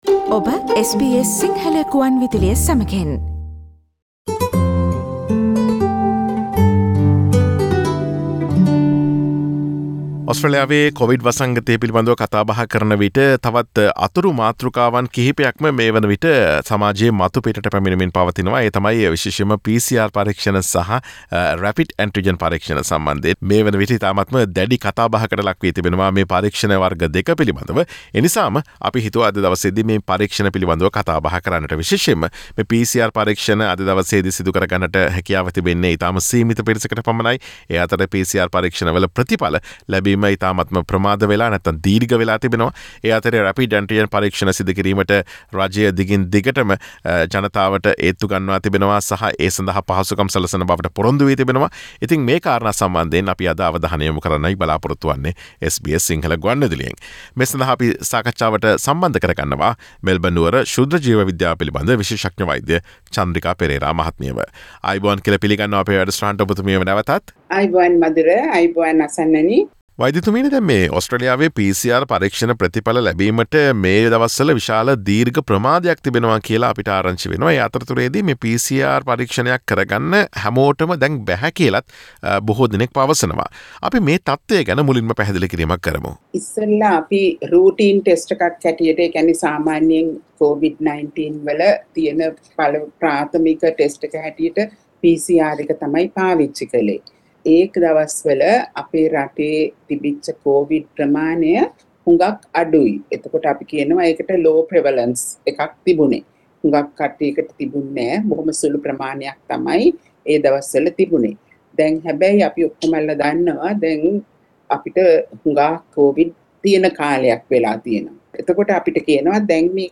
ඔස්ට්‍රේලියාවේ PCR පරීක්ෂණ කිරීම සීමා කර ඇතිද සහ Rapid Antigen පරීක්ෂණ හා PCR පරීක්ෂණ කරගත හැකි පිරිස් කවුරුන්ද යන්න පිළිබඳ SBS සිංහල ගුවන් විදුලිය සිදුකළ සාකච්ඡාවට සවන් දීමට ඉහත ඡායාරූපය මත ඇති speaker සලකුණ මත click කරන්න.